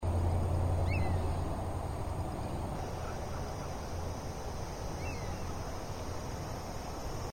340hachikuma_nakigoe.mp3